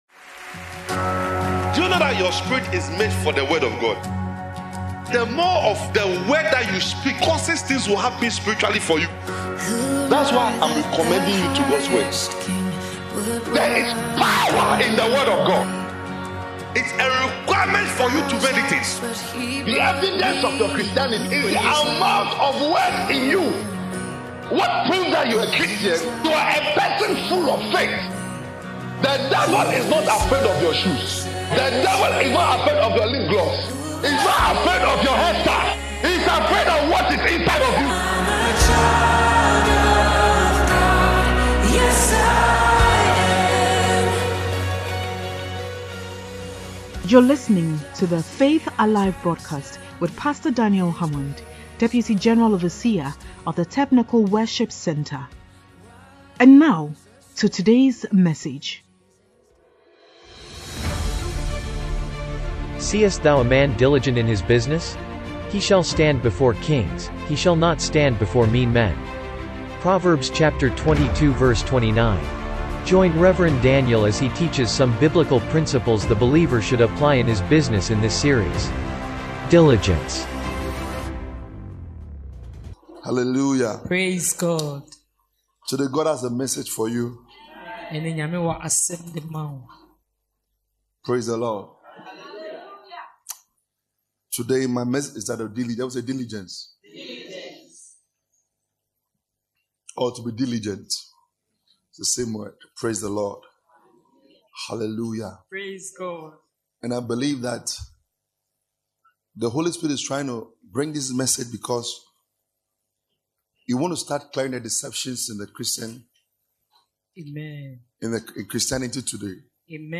All New Creation Sermons